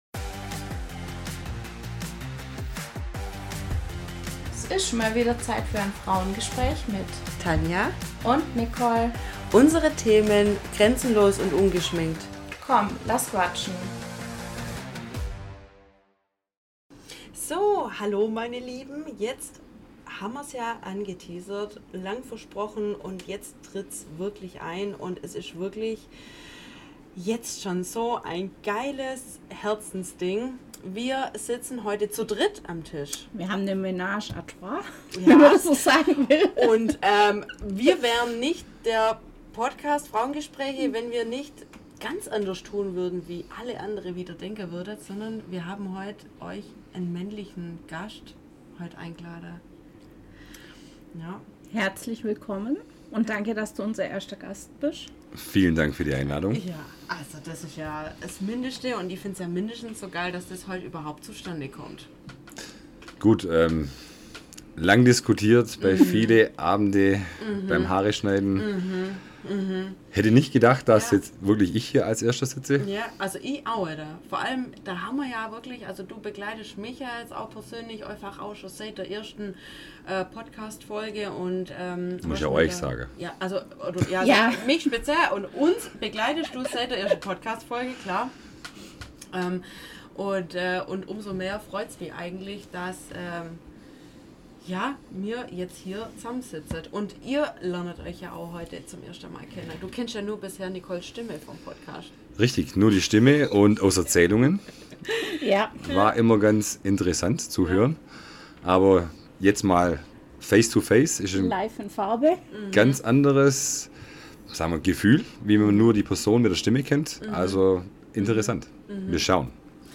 Naheliegend kann schließlich jeder und wie ihr hört, wir hatten jede Menge Spaß. Ob Frau oder Mann, wir meinen jeder struggelt im Leben mal mit dem Älter werden.